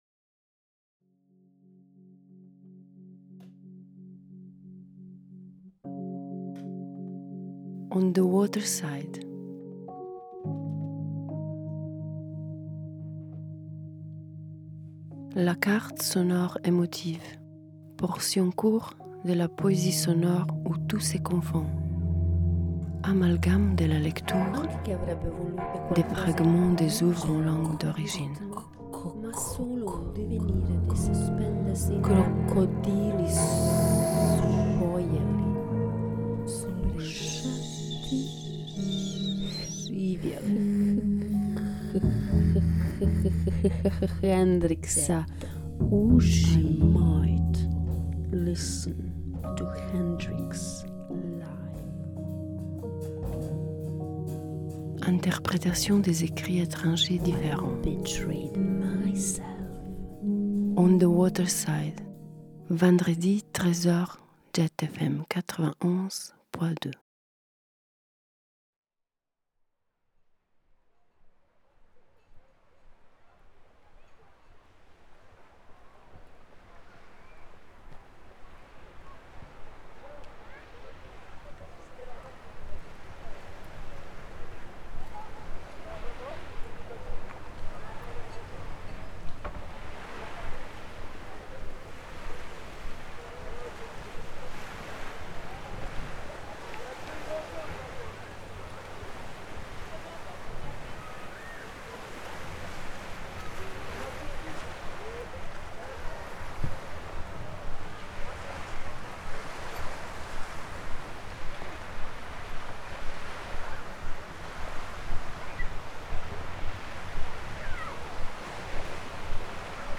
C’est le titre de poème de Mário Andrade que je partage avec vous à ma manière, melangé avec les fragments de son que j’ai pris cette samedi sur le Gallant et autour.